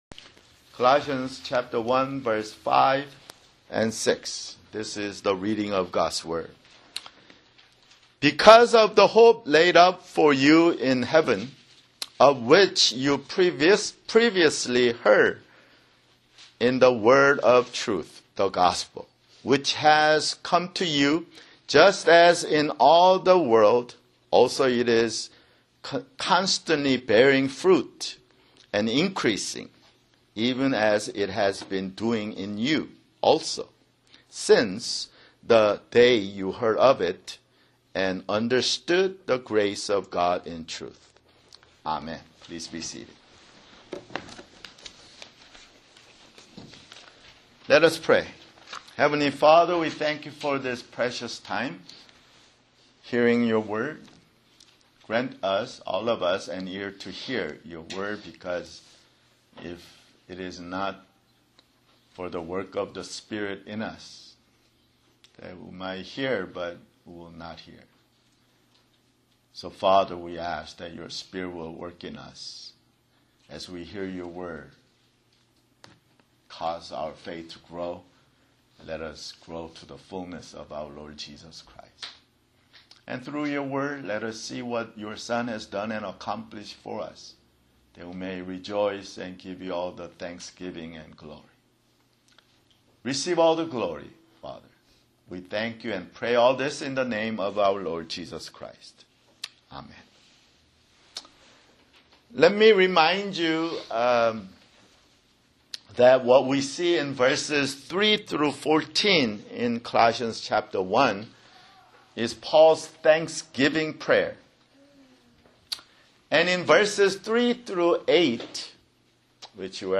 [Sermon] Colossians (14)